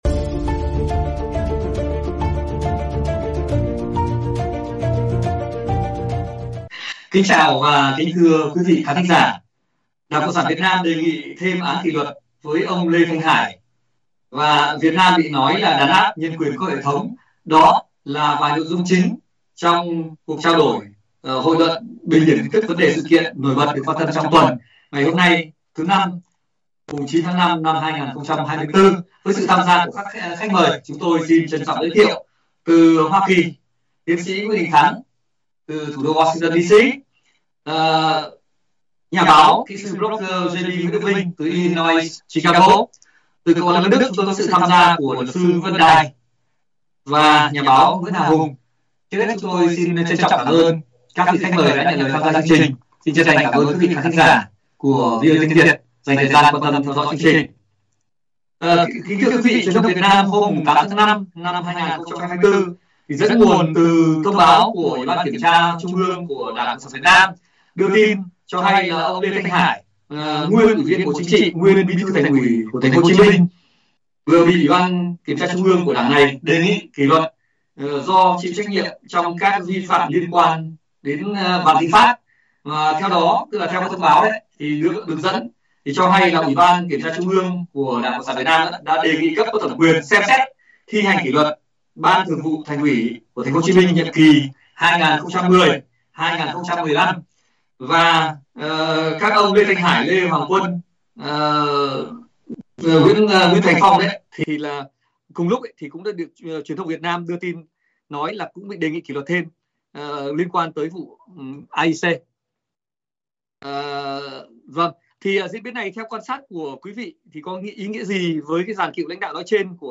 Các khách mời là luật sư, nhà báo, nhà quan sát thời sự, chính trị bình luận, phân tích một số diễn biến, tin tức thời sự đáng chú ý trong tuần, trong đó có việc cựu Bí thư Thành ủy TPHCM ông Lê Thanh Hải mới bị UBKT TW của ĐCSVN đề nghị kỷ luật liên quan vụ Vạn Thịnh Phát và AIC.